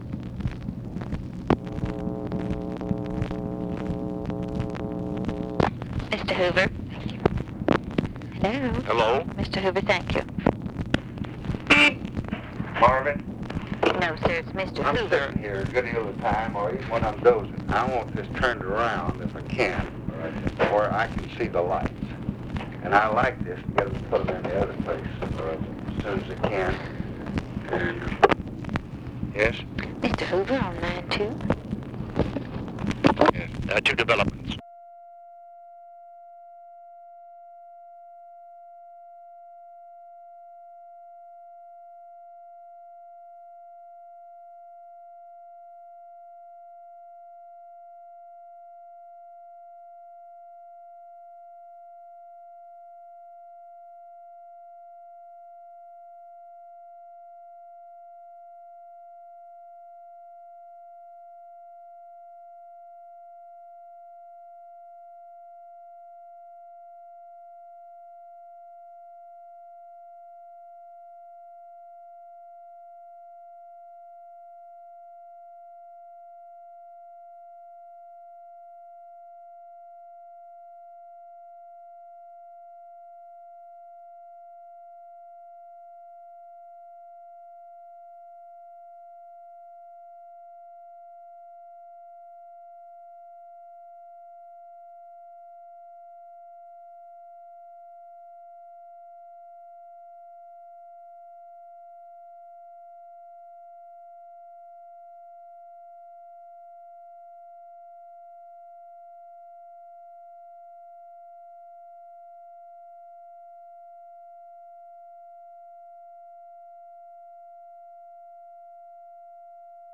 Conversation with J. EDGAR HOOVER and OFFICE CONVERSATION, May 18, 1965
Secret White House Tapes